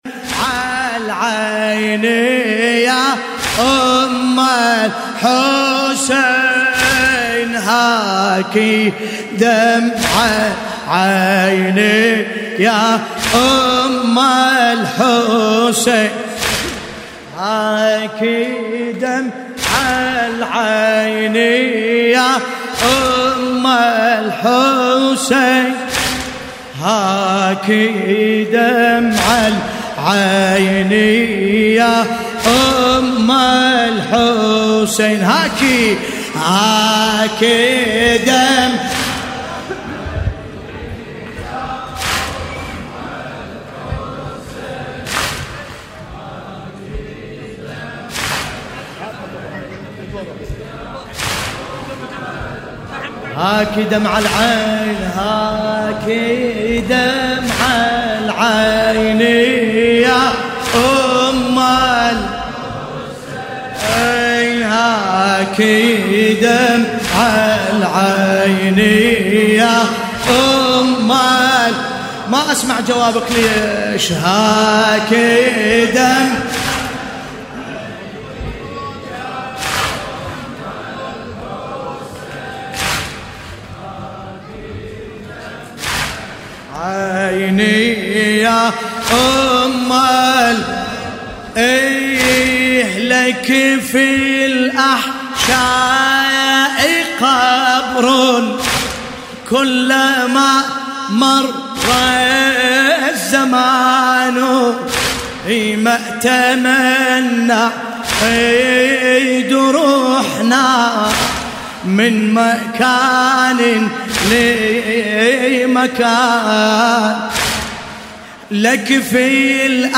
تحميل : هاك دمع عيني يا أم الحسين .. لك في الأحشاء قبر كلما مر الزمان / الرادود باسم الكربلائي / اللطميات الحسينية / موقع يا حسين